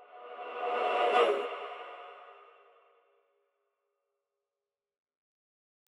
AV_Vocal_Riser_FX
AV_Vocal_Riser_FX.wav